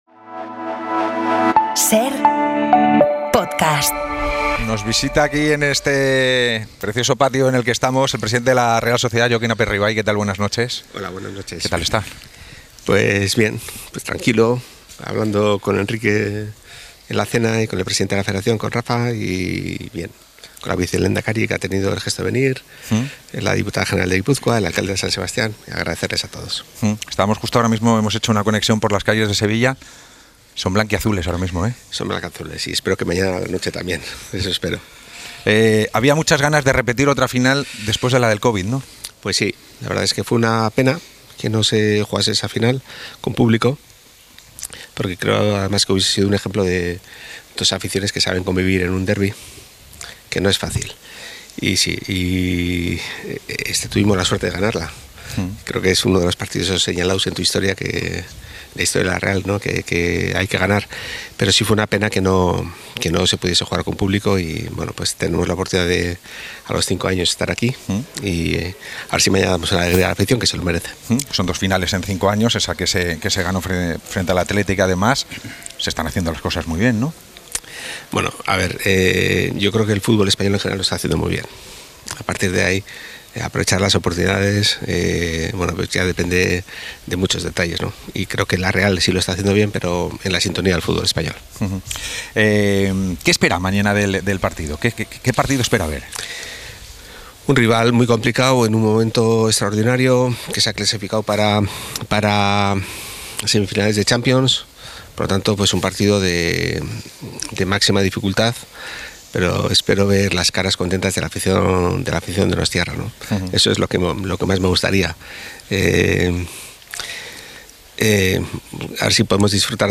El presidente de la Real Sociedad desvela en la noche especial de 'El Larguero' por la final de la Copa por qué eligieron a Pellegrino Matarazzo.